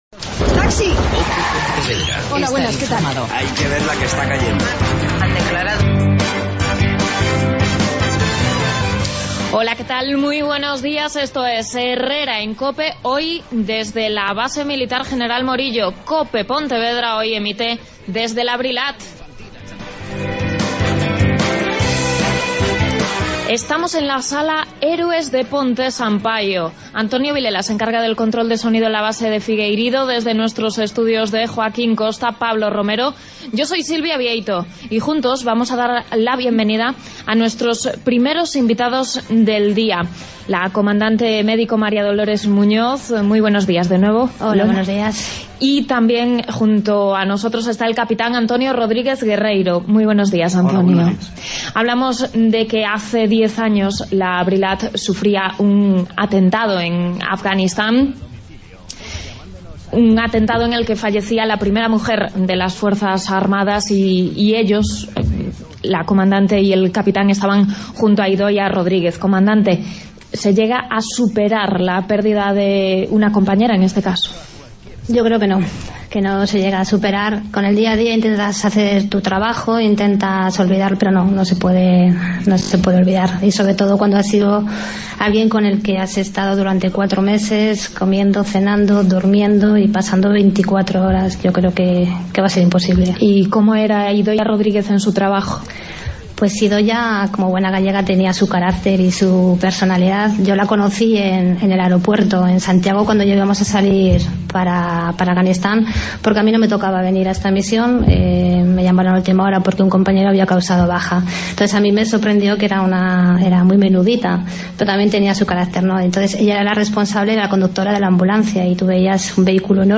AUDIO: Hoy programa especial desde la BRILAT en Pontevedra.